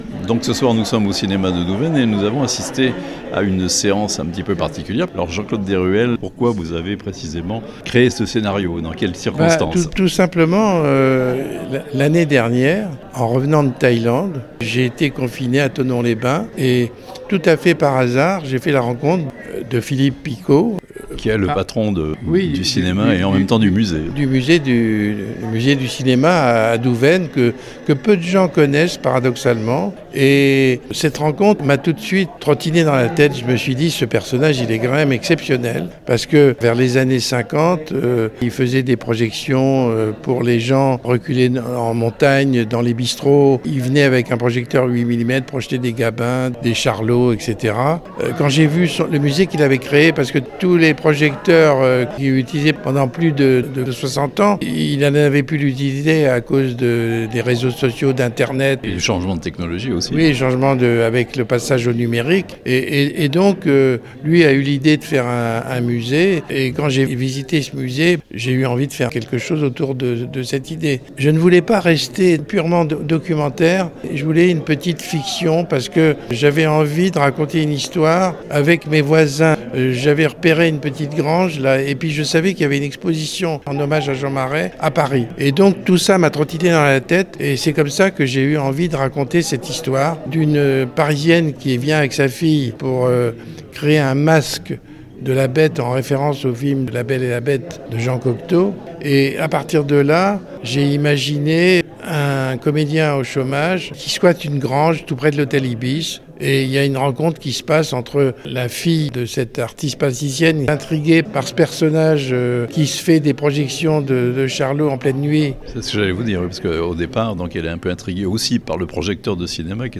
Un film pour découvrir le musée du cinéma de Douvaine (interview)